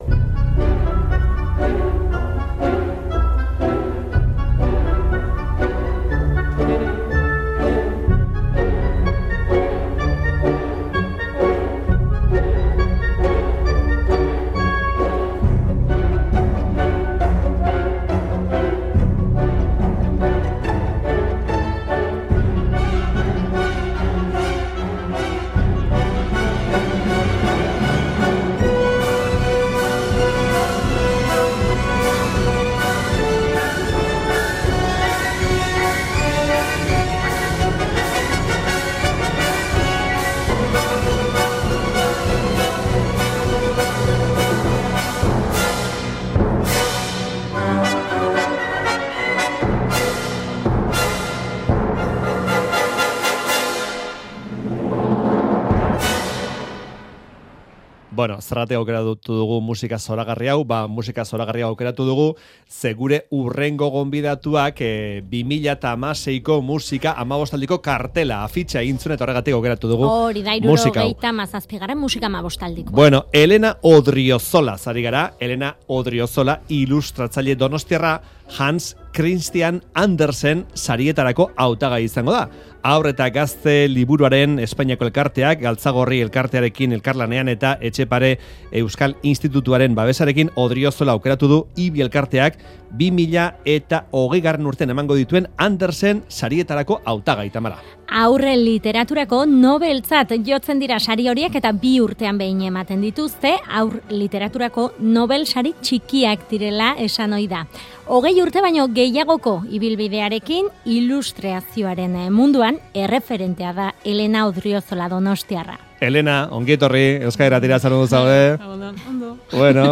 Donostiako Haur Liburutegira joan gara bere lana ikustera